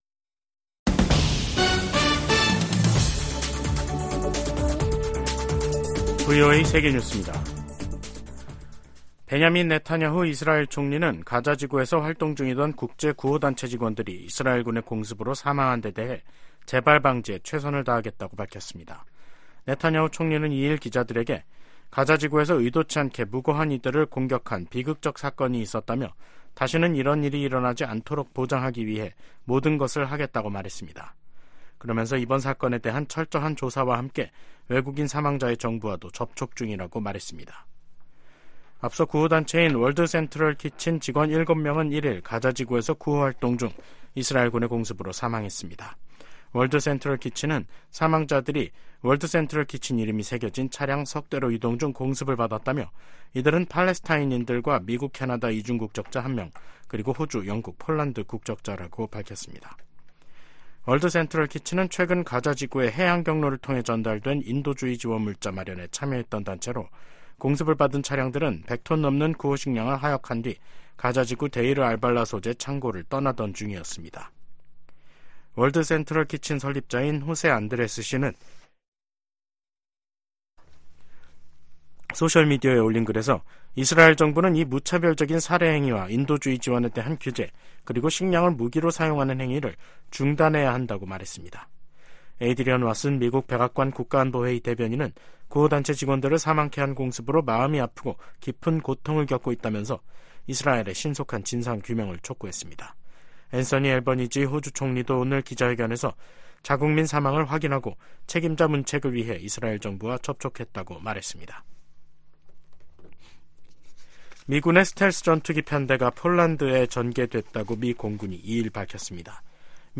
VOA 한국어 간판 뉴스 프로그램 '뉴스 투데이', 2024년 4월 2일 3부 방송입니다. 북한이 보름 만에 또 다시 중거리 극초음속 미사일로 추정되는 탄도미사일을 동해상으로 발사했습니다. 미국은 러시아가 북한 무기를 받은 대가로 유엔 대북제재 전문가패널의 임기 연장을 거부했다고 비판했습니다. 주한미군이 중국과 타이완 간 전쟁에 참전할 경우 한국도 관여를 피하기 어려울 것으로 전 주일미군사령관이 전망했습니다.